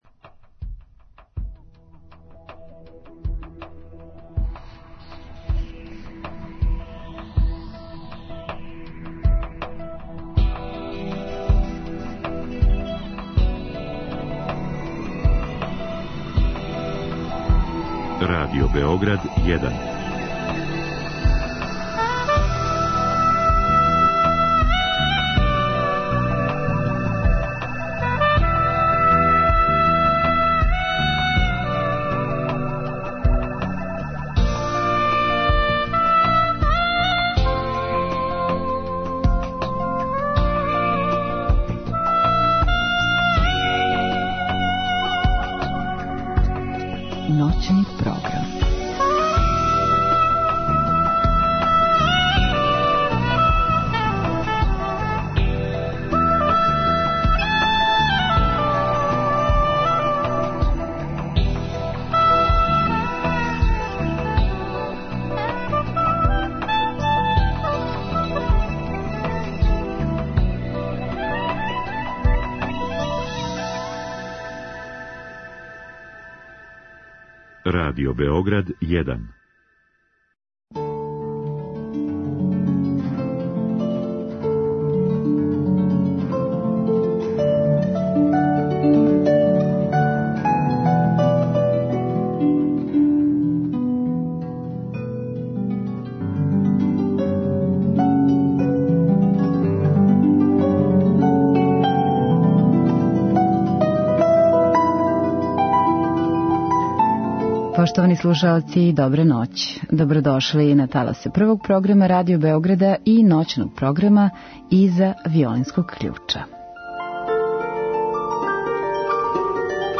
У наставку емисије слушаћемо композиције Дебисија, Моцарта, Арнолда Бакса и Брамса.